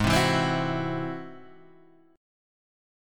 G#+7 chord